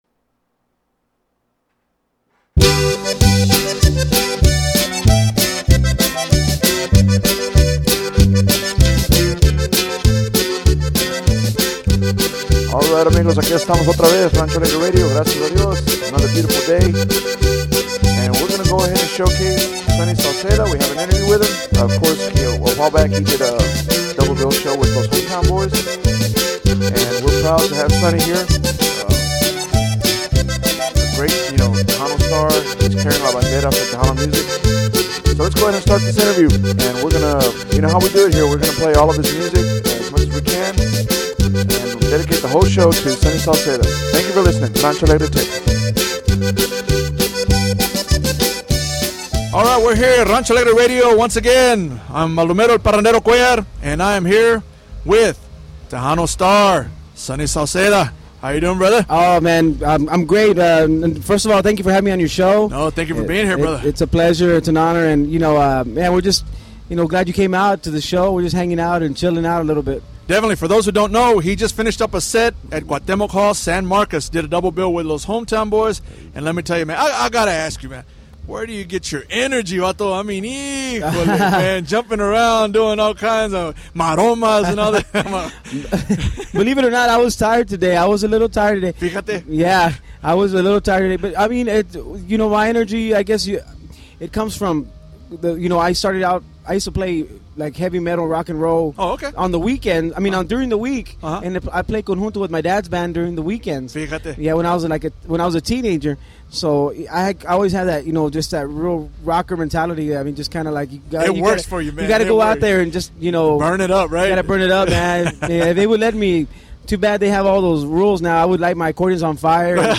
Rancho Alegre Interview